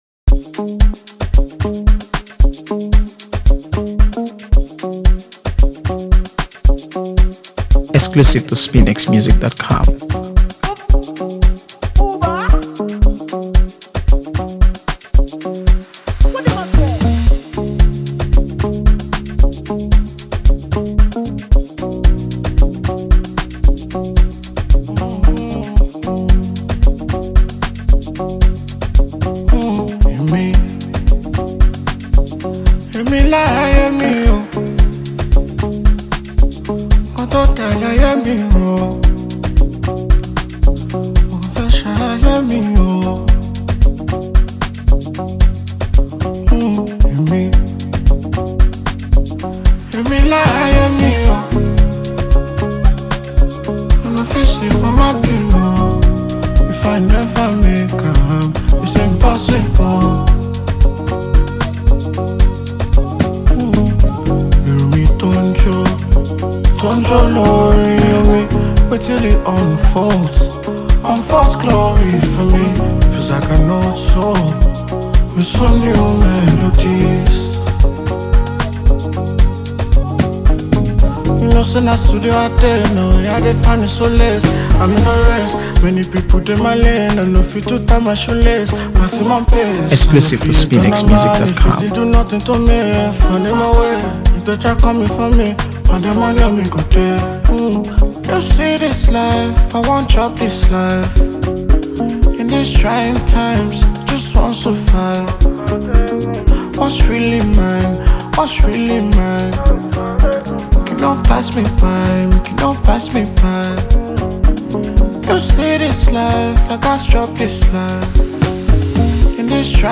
AfroBeats | AfroBeats songs
warm, infectious, and easy to keep on repeat